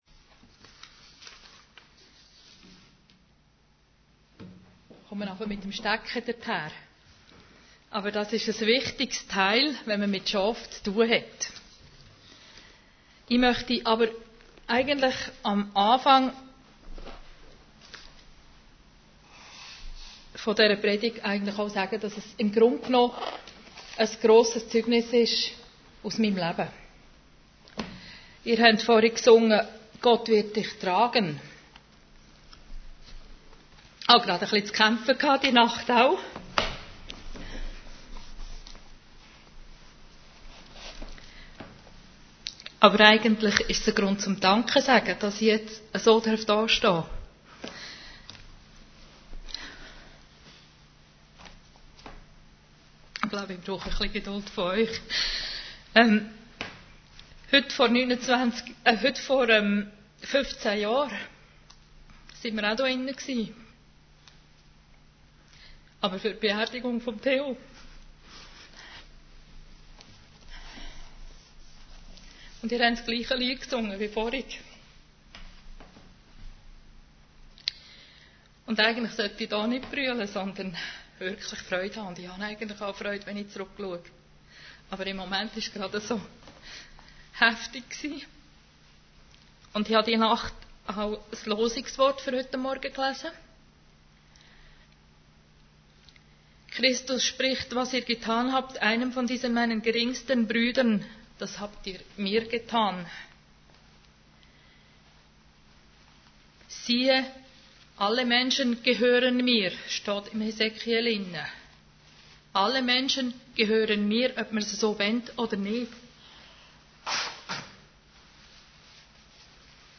Gastpredigerin